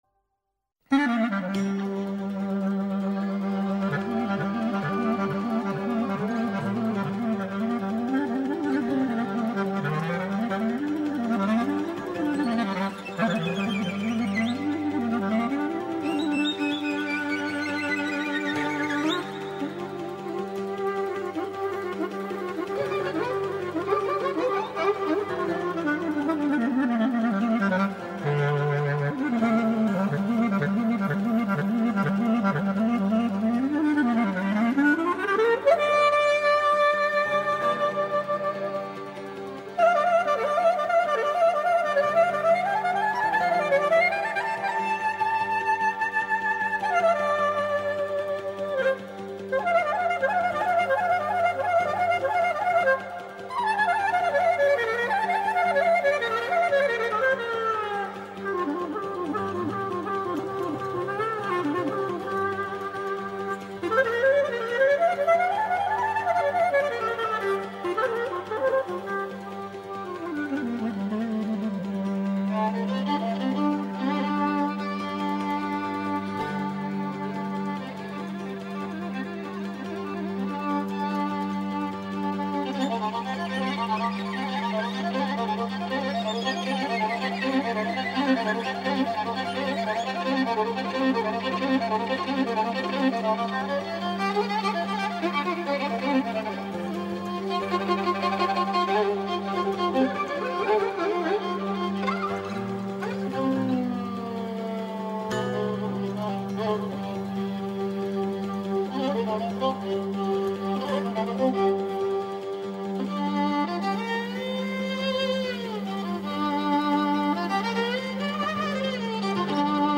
Ένα καθημερινό μουσικό ταξίδι στην παράδοση της Ελλάδας. Παλιές ηχογραφήσεις από το αρχείο της Ελληνικής Ραδιοφωνίας, νέες κυκλοφορίες δίσκων καθώς και νέες ηχογραφήσεις από τα Μουσικά Σύνολα της ΕΡΤ.